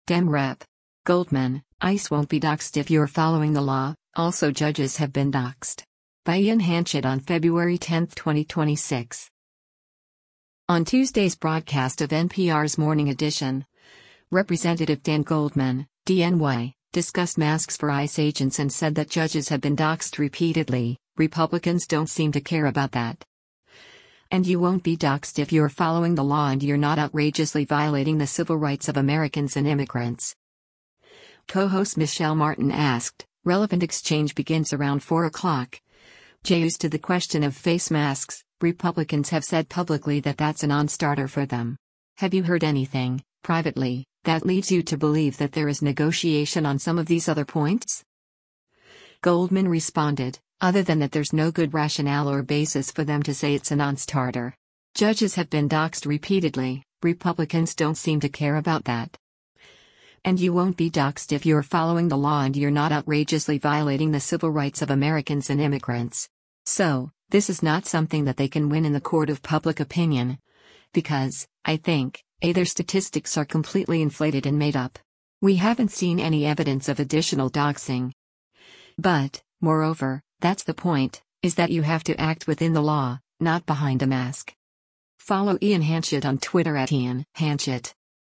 On Tuesday’s broadcast of NPR’s “Morning Edition,” Rep. Dan Goldman (D-NY) discussed masks for ICE agents and said that “Judges have been doxxed repeatedly, Republicans don’t seem to care about that. And you won’t be doxxed if you’re following the law and you’re not outrageously violating the civil rights of Americans and immigrants.”